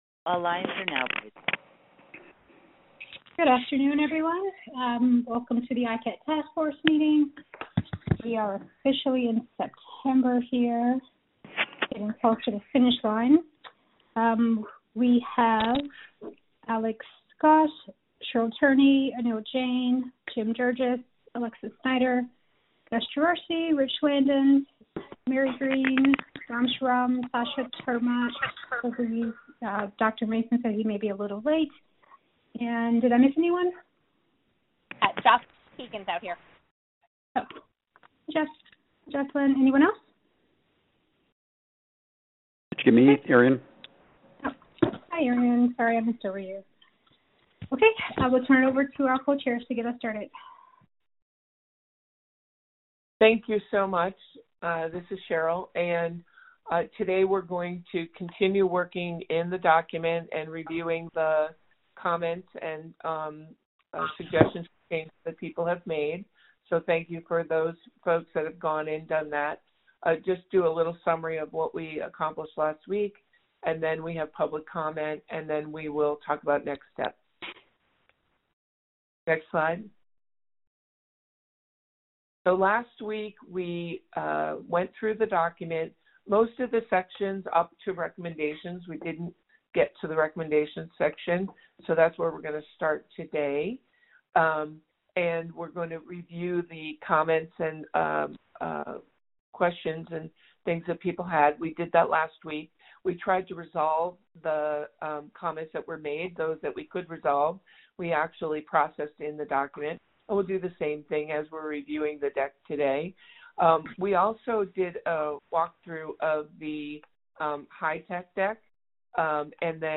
Intersection of Clinical and Administrative Data (ICAD) Task Force Meeting Audio 9-1-2020